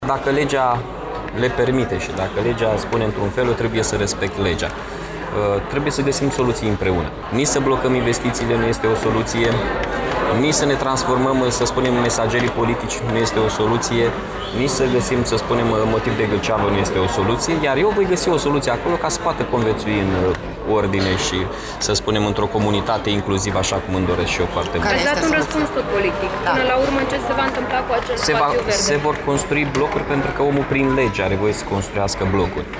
Dimineaţă, primarul Iaşului, Mihai Chirica, a precizat că nu poate interzice proiectele imobiliare din zona Oancea atât timp cât acestea respectă legislaţia în vigoare.